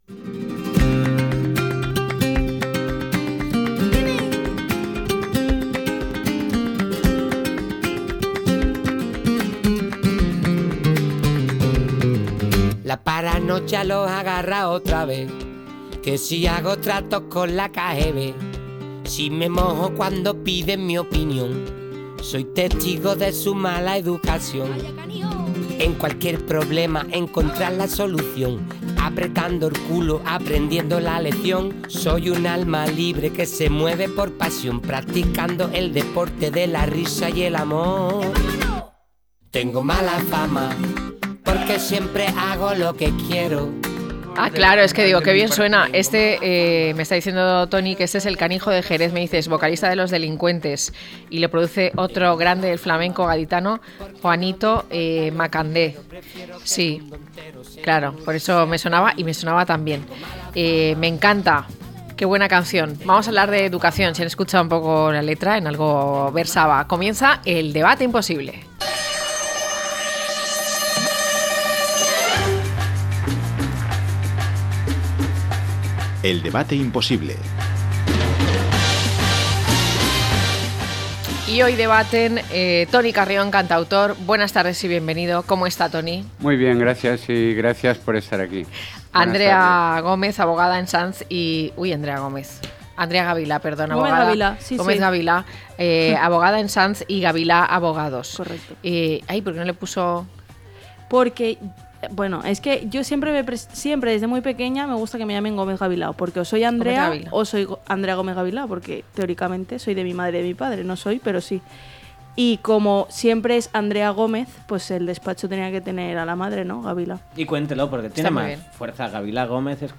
I+D+I y estrategias para copiar en exámenes, a debate - La tarde con Marina